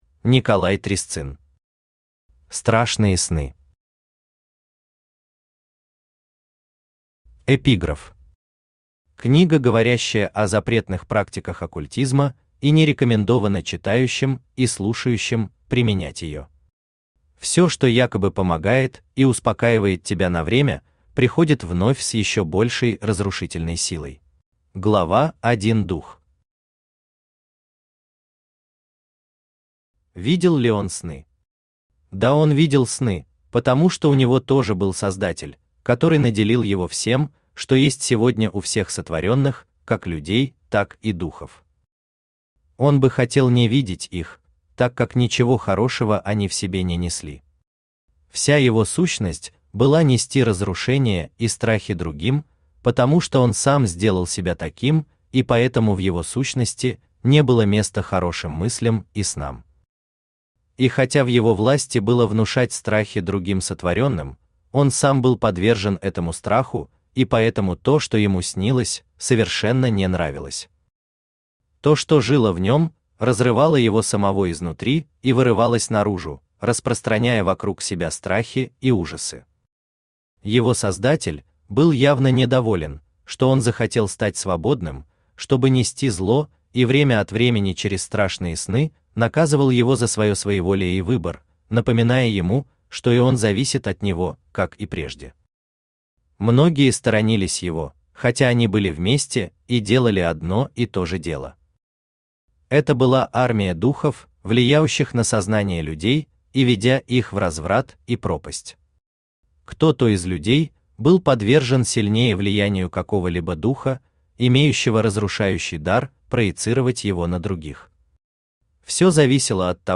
Aудиокнига Страшные сны Автор Николай Трясцын Читает аудиокнигу Авточтец ЛитРес.